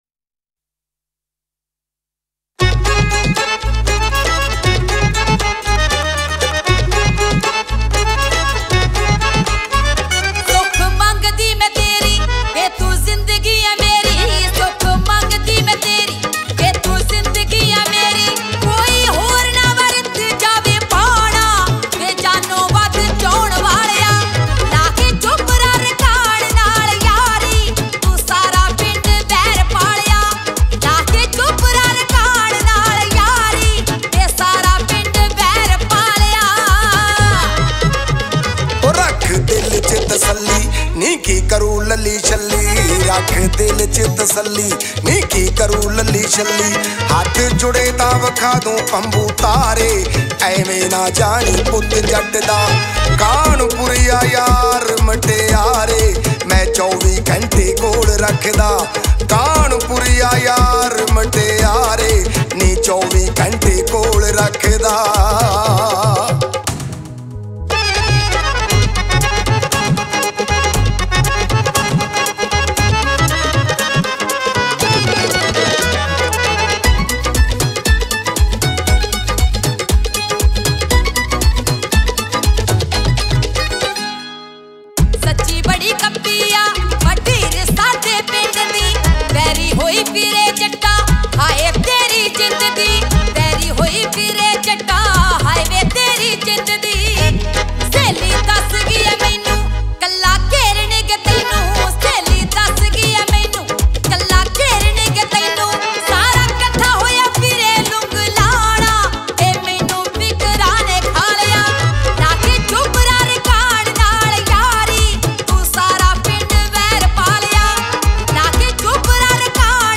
old Punjabi song